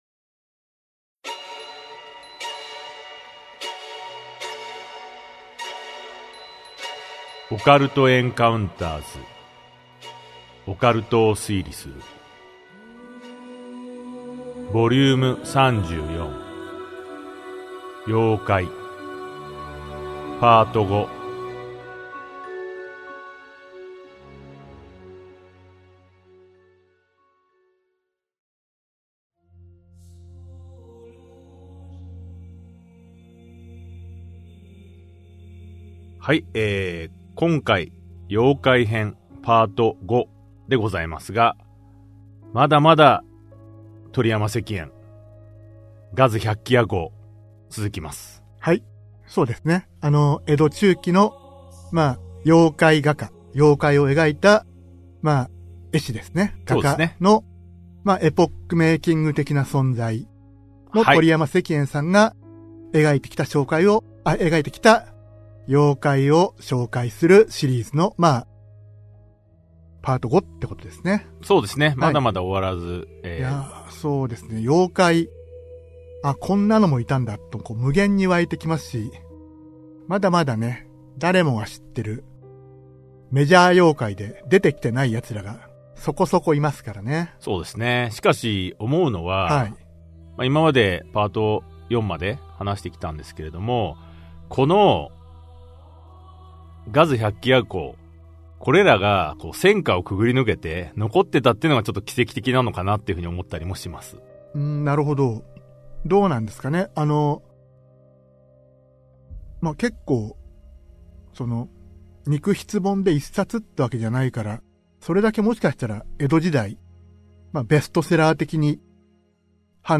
[オーディオブック] オカルト・エンカウンターズ オカルトを推理する Vol.34 妖怪 5
オカルト・エンカウンターズの二人が伝承と文献を紐解き、伝説の裏側を推理する──。